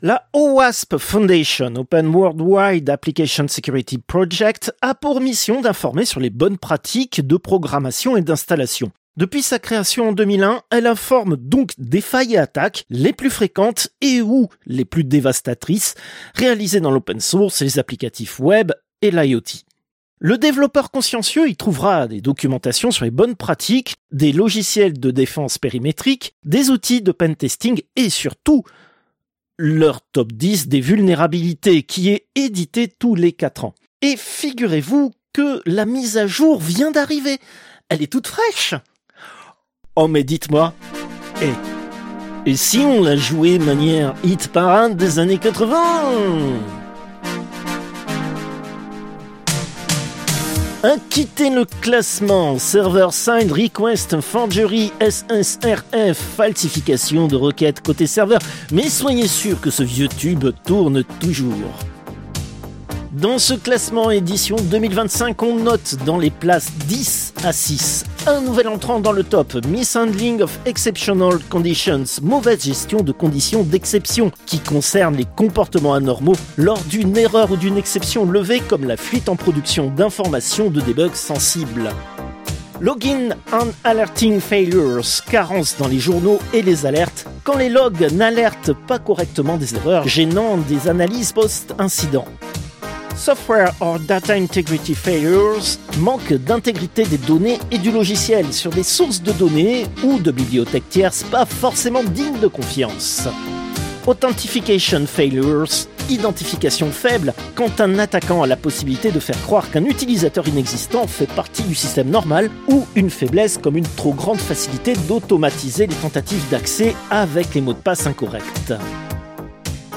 Extrait de l'émission CPU release Ex0228 : lost + found (janvier 2026).